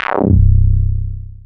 Filta Bass (JW2).wav